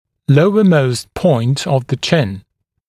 [‘ləuəməust pɔɪnt əv ðə ʧɪn][‘лоуэмоуст пойнт ов зэ чин]самая нижняя точка подбородка